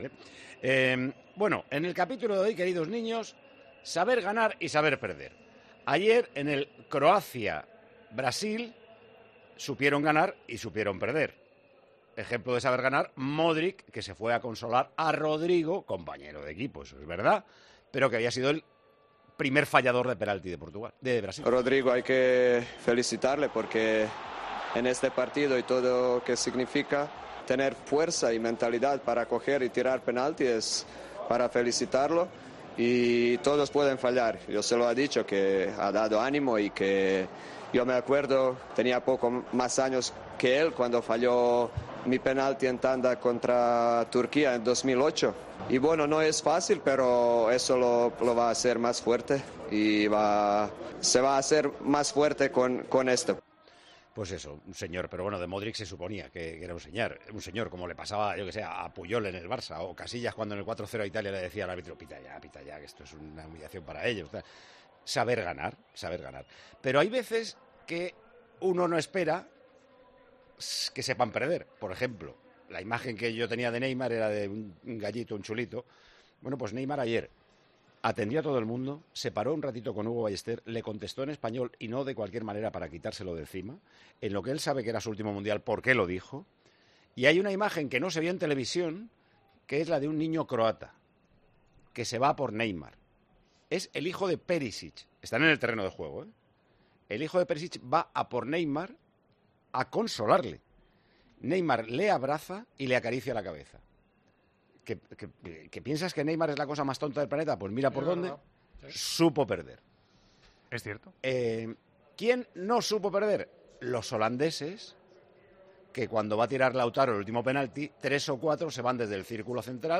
El director de 'Tiempo de Juego' comenta las diferencias sobre lo que se vio en el Croacia-Brasil y en el Argentina-Países Bajos: "Modric consoló a Neymar; Messi hizo lo contrario"